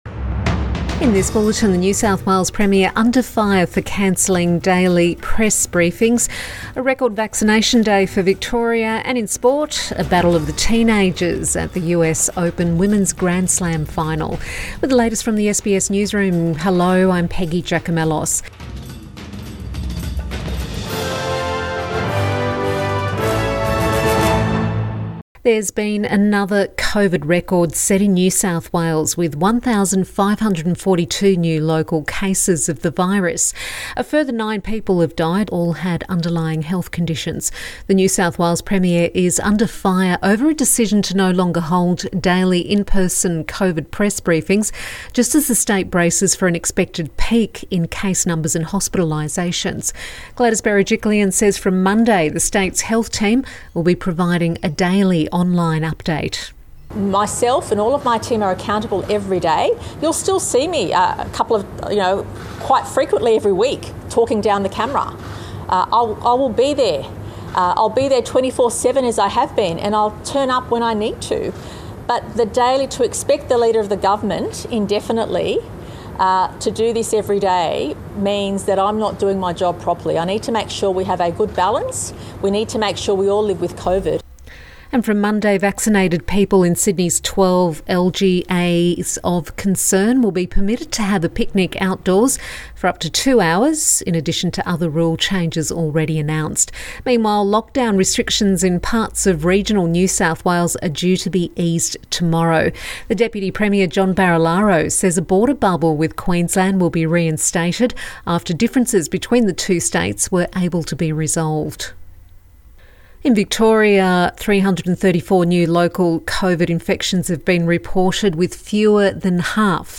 PM bulletin 10 September 2021